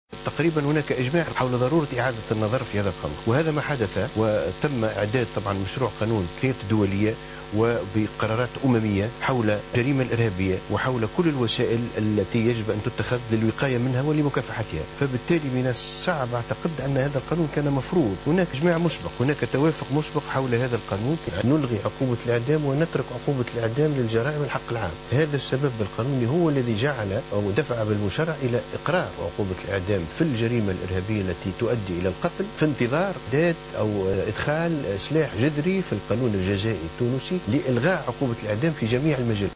وأوضح محمد الصالح بن عيسى في حوار مع قناة "بي بي سي" عشية اليوم الأربعاء أن هناك اجماع حول ضرورة إعادة النظر في هذا القانون وهو ما حدث حيث تم إعداد مشروع قانون بقرارات أممية حول الجريمة الإرهابية وكل الوسائل التي يجب أن تتخذ للوقاية منها ومكافحتها وفق قوله.